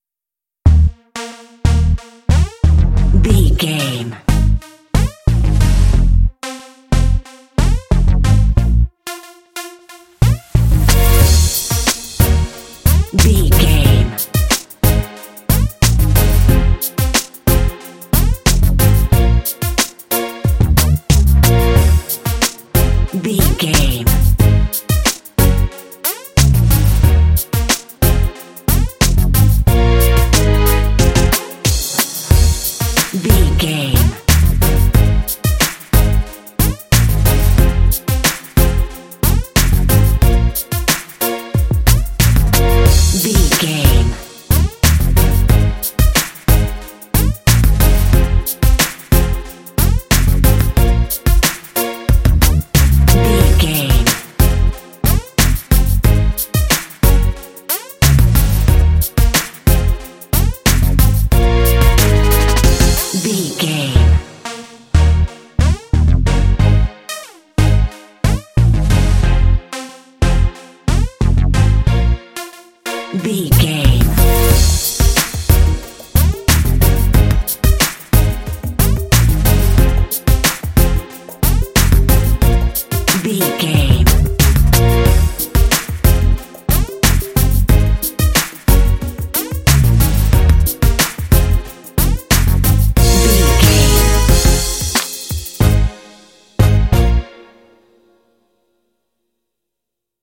Aeolian/Minor
B♭
cool
urban
futuristic
synthesiser
drums
bass guitar
strings
synth-pop